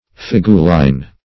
figuline - definition of figuline - synonyms, pronunciation, spelling from Free Dictionary
Figuline \Fig"u*line\ (? or ?), n. [F., fr. L. figulina pottery,